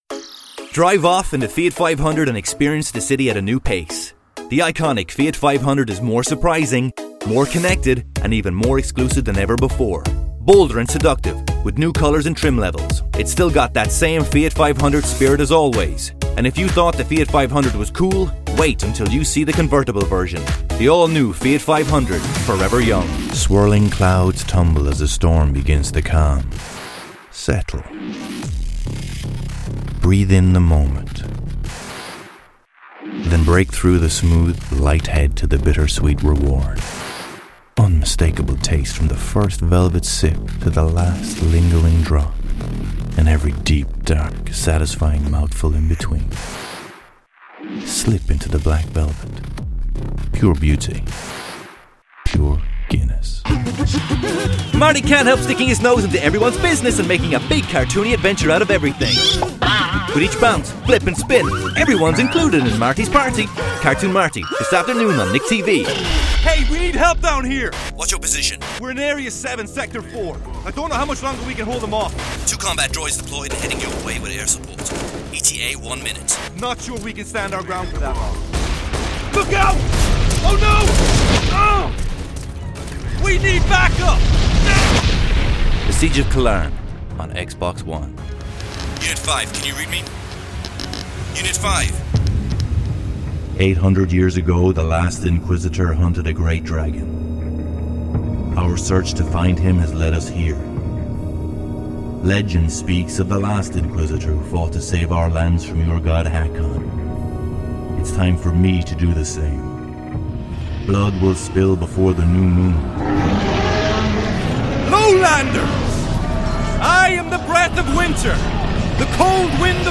Male
30s/40s, 40s/50s
Irish Neutral